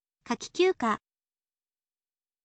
kaki kyuuka